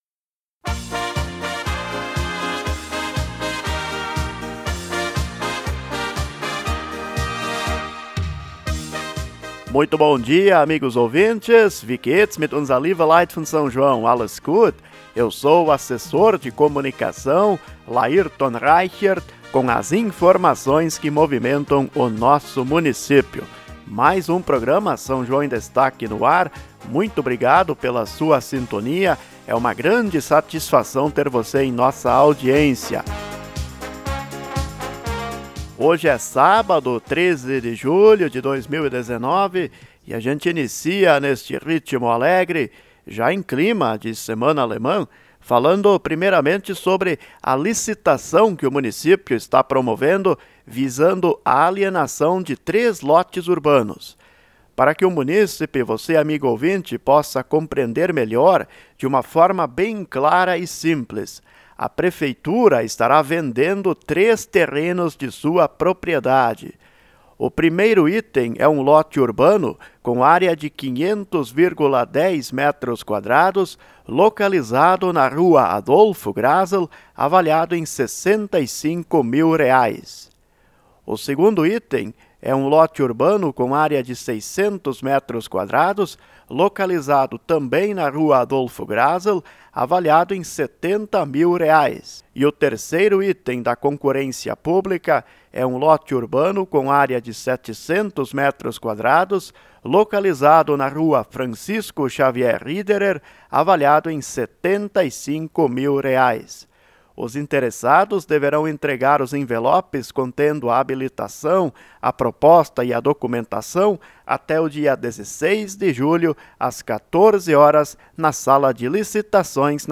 Programa “São João em Destaque”. As notícias, avisos, entrevistas e principais ações da Administração Municipal você acompanha nos programas semanais de rádio.